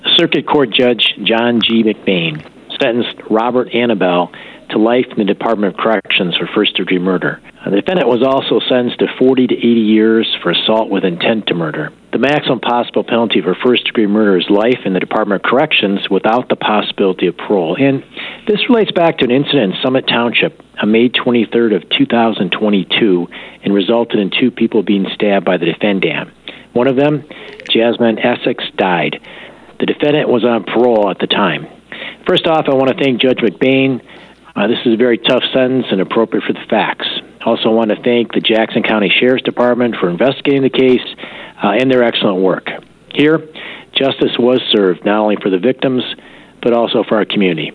Prosecutor Jerry Jarzynka joined WKHM with the details: